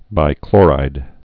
(bī-klôrīd)